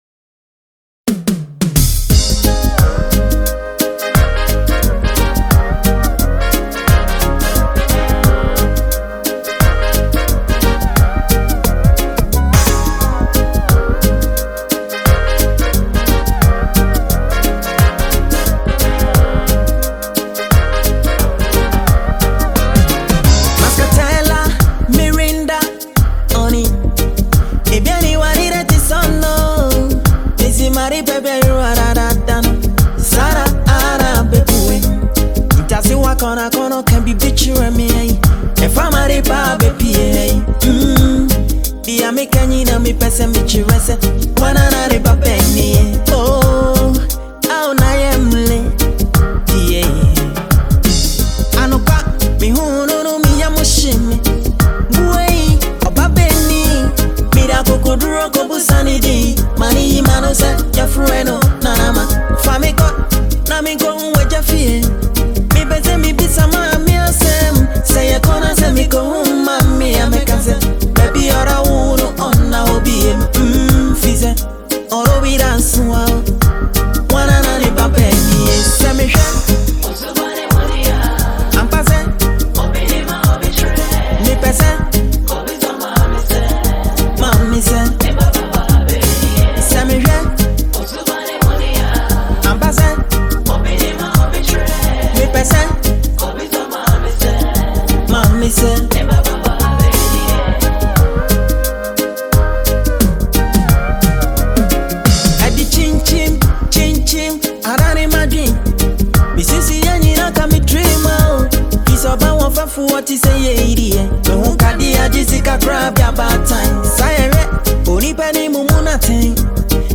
Ghanaian highlife